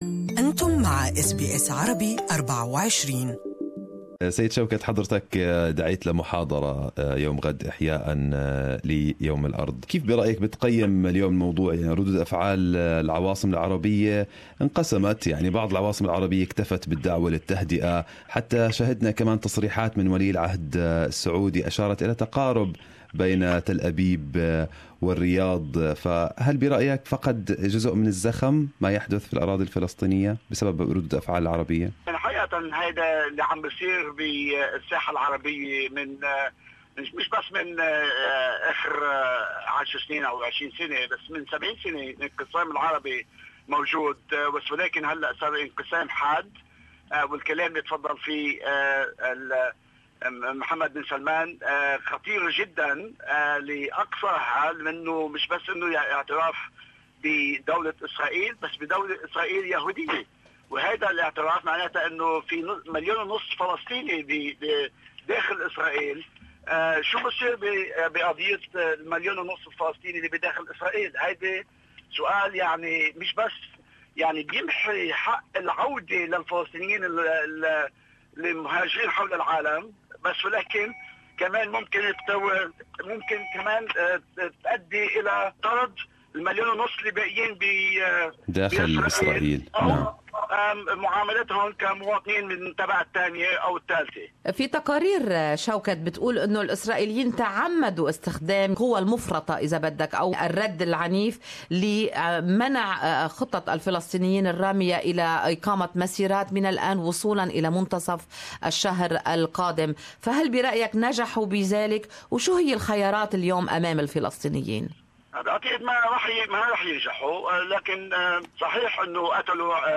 Good Morning Australia interviewed NSW senator Shawkat Maslamani to talk about the upcoming lecture to commemorate "Earth Day" and discuss the expected announcement to recognize the state of Palestine in the party's convention by the end of April 2018